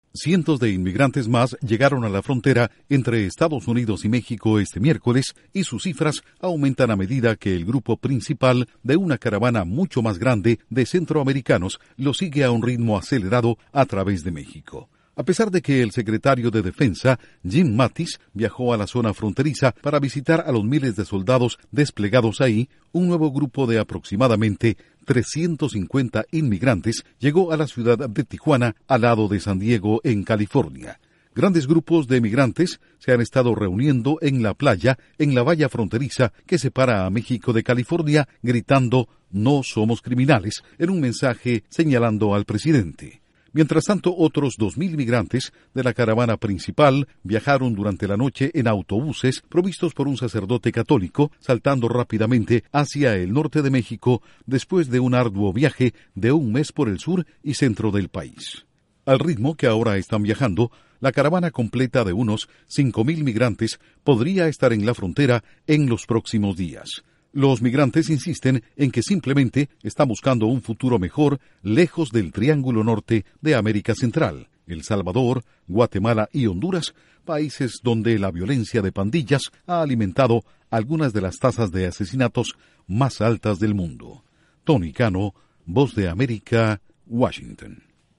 La caravana inmigrante sigue con velocidad; más migrantes llegan a la frontera de Estados Unidos con México. Informa desde la Voz de América en Washington